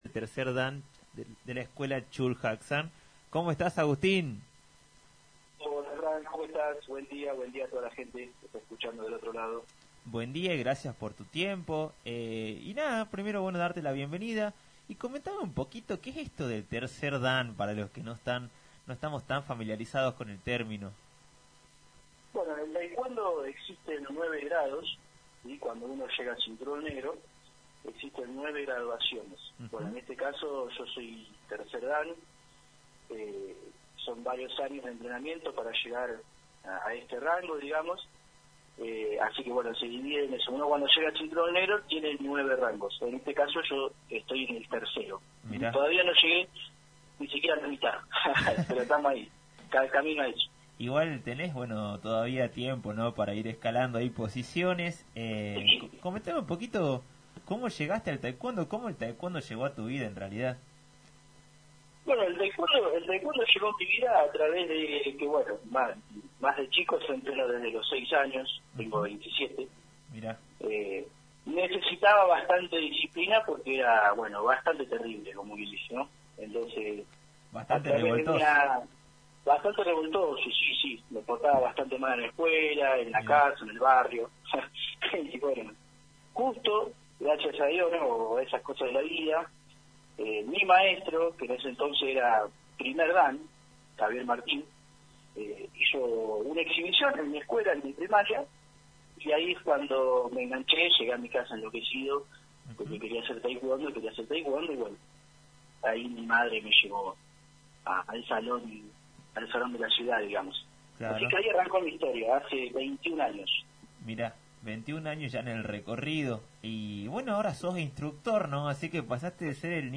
En comunicación telefónica con radio Cadena Máxima, un representante del Taekwondo Chul Hak San, transmitió los valores esenciales de la práctica del arte marcial, y como la educación elegida por los profesores, va más allá del hecho de aprender solo a defenderse.